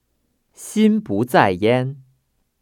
[xīn bú zài yān] 신부짜이얜